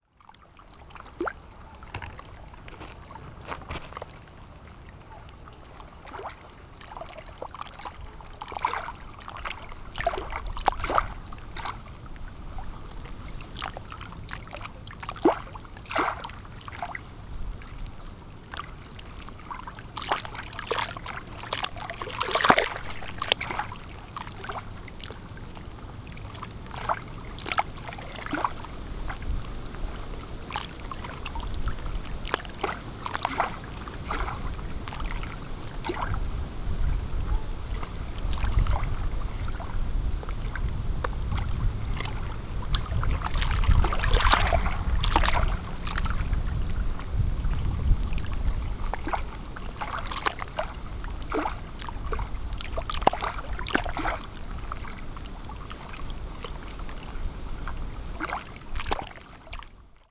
On avait dit aux amis que l’on arriverait dans l’après-midi et il était un peu tôt ; alors on avait marché un peu au bord du lac et j’en avais profité pour enregistrer les vaguelettes qui tapaient contre la berge.
Aveyron, août 2024